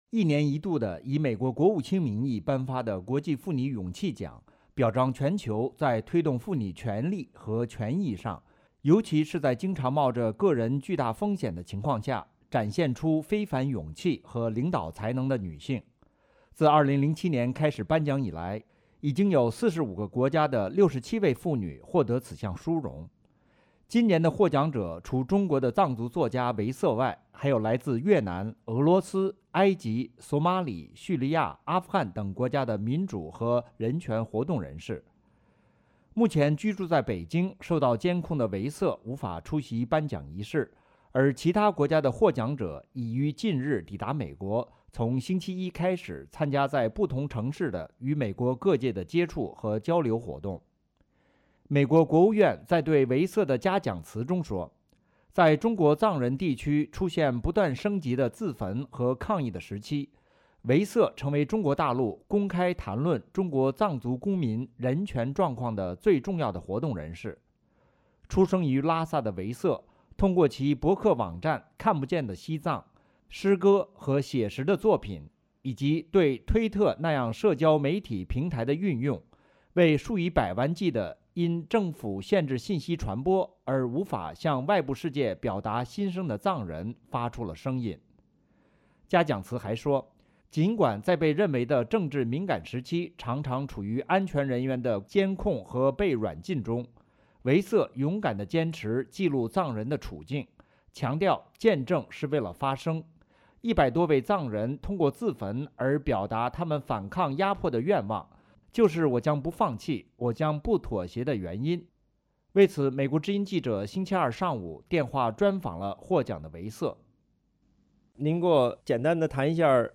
专访：藏族作家唯色荣获美国国际妇女勇气奖